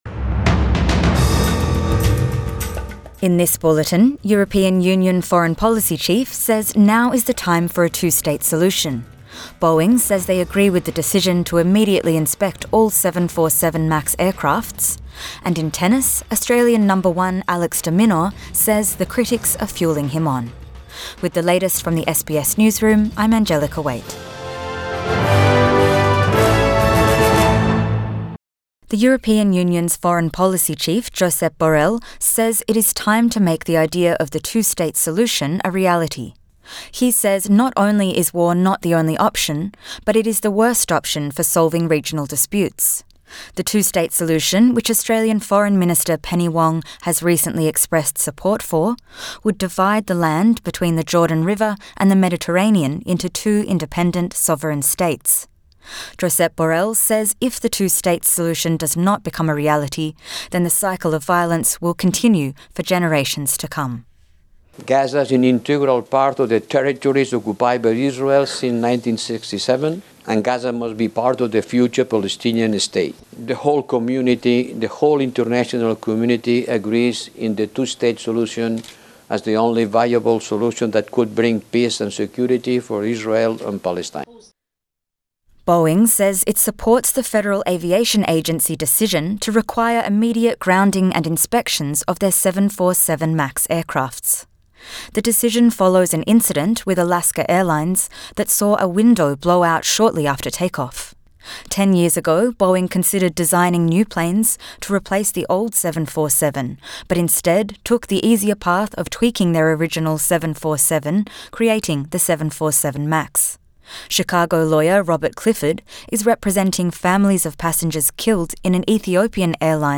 Midday News Bulletin 7 January 2024